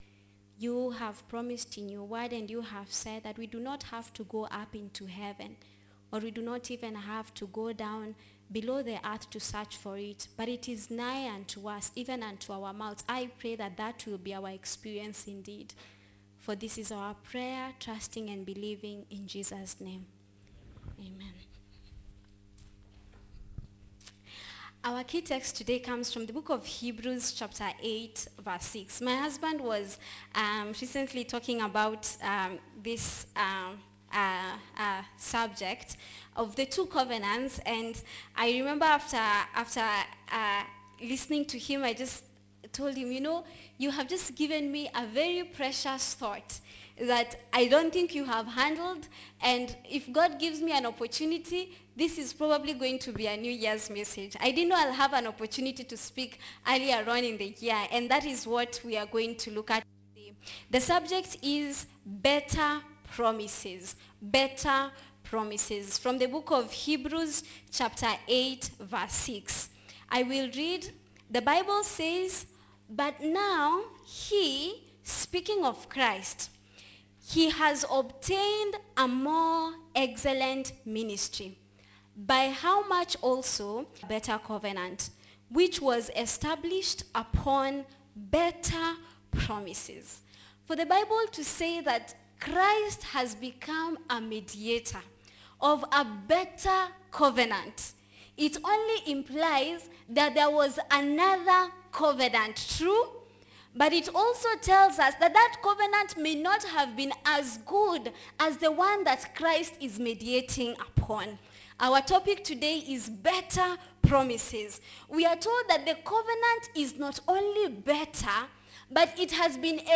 Tuksda Church -Sermons